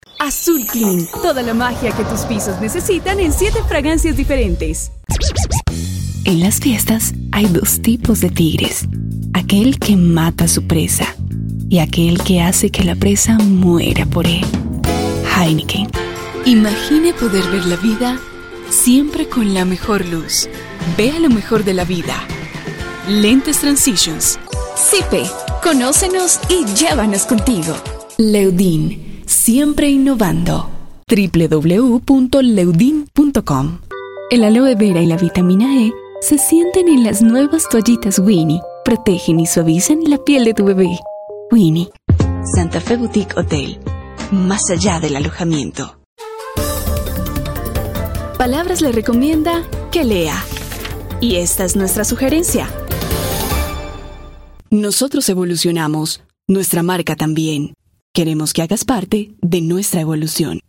Commercial Voice Over